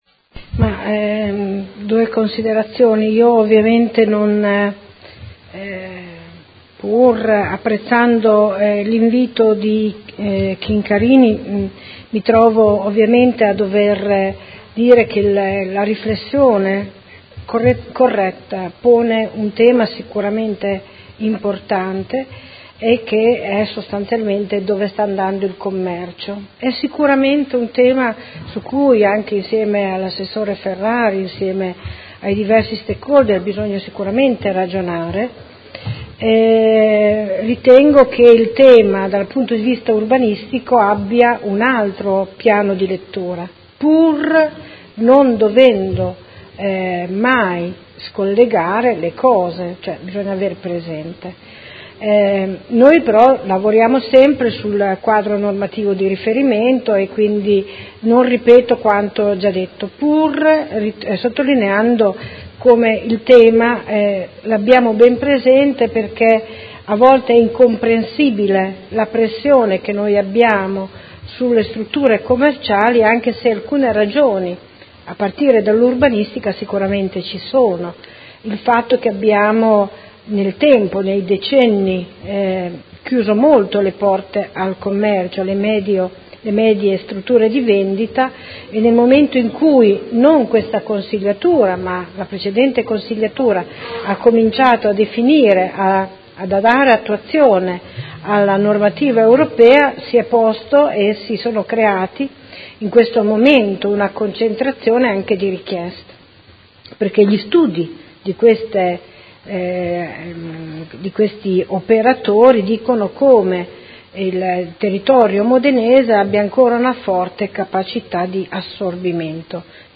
Seduta del 13/07/2017 Conclusioni a Dibattito.